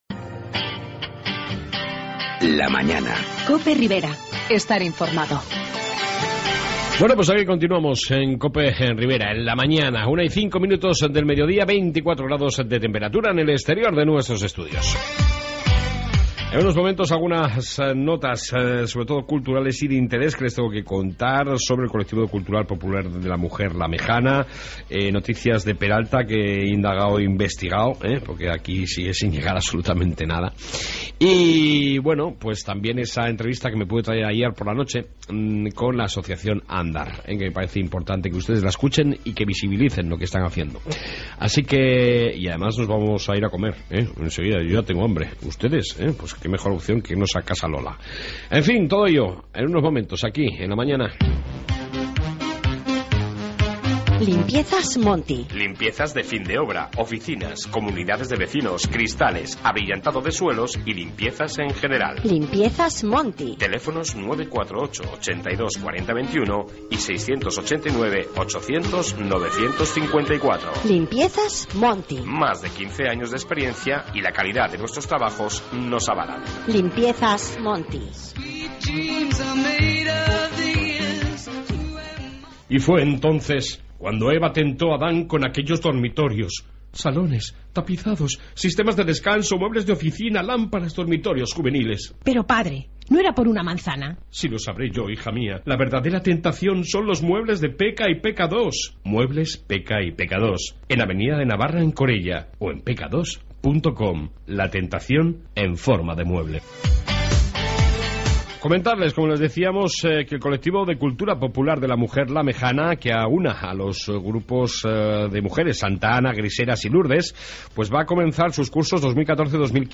AUDIO: Amplia Entrevista con la asociacion Andar de la Ribera que prepara unas jornadas sobre el TDAH en la UPNA de Tudela